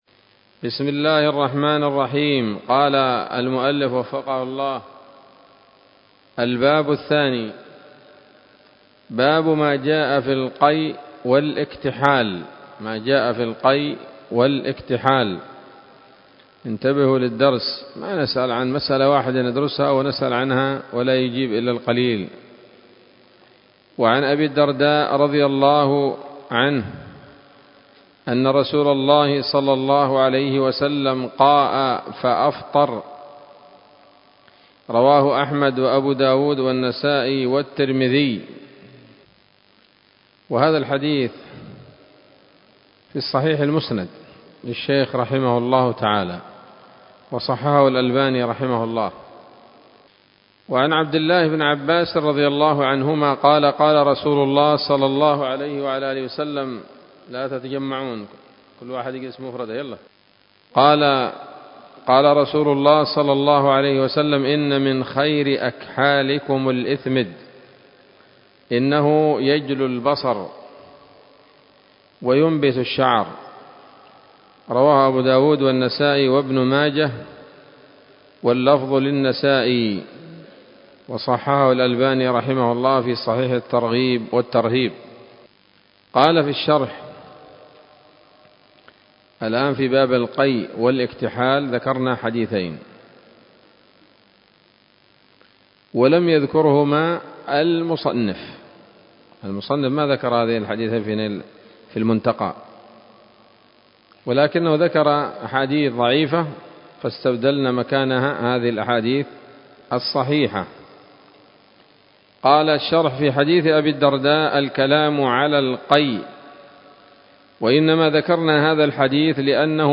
الدرس السادس من كتاب الصيام من نثر الأزهار في ترتيب وتهذيب واختصار نيل الأوطار